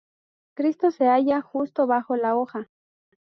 Pronounced as (IPA) /ˈxusto/